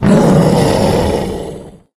izlome_death_6.ogg